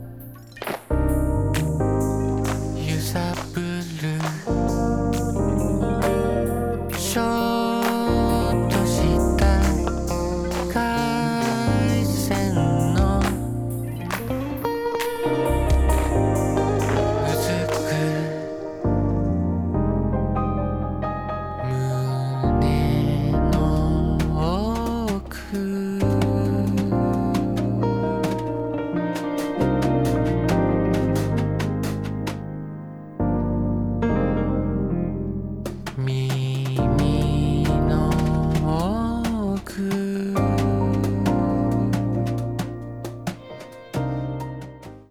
Japanese New Wave / Rock Pop